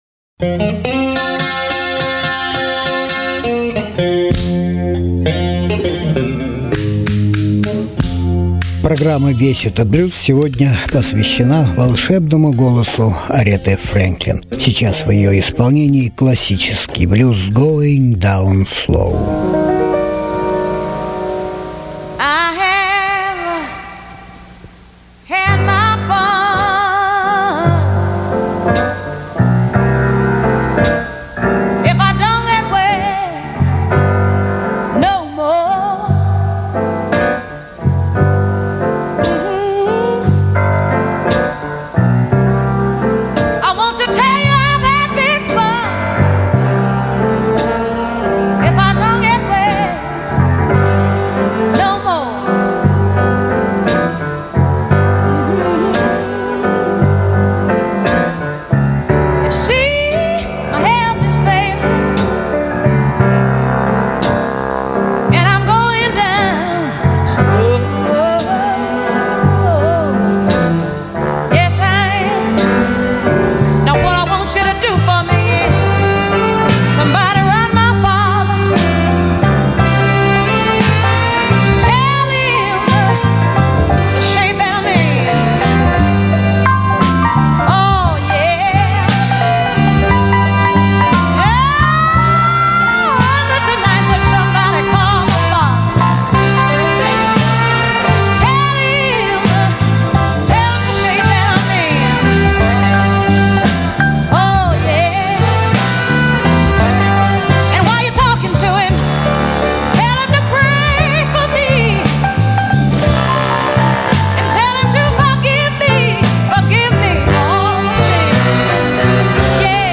американская певица в стилях ритм-энд-блюз, соул и госпел.
Жанр: Блюзы и блюзики